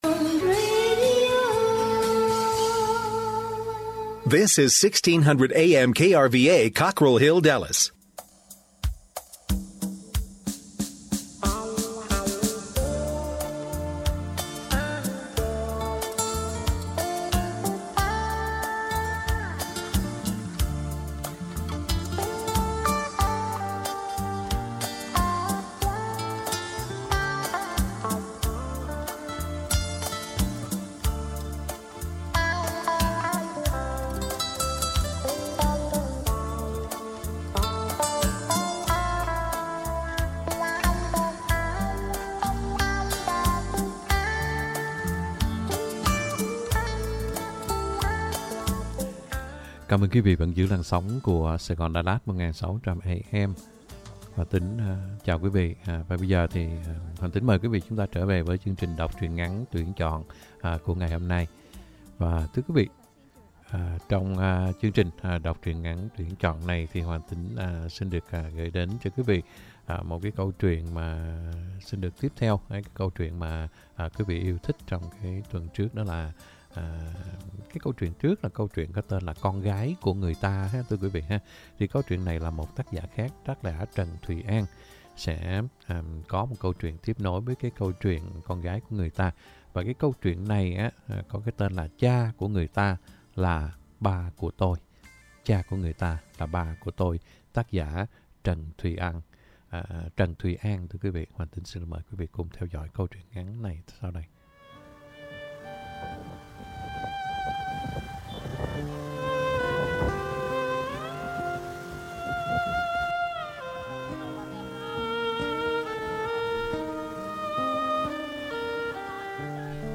Đọc Truyện Ngắn = Cha Của Người Ta , Là Ba Của Tôi - 06/14/2022 .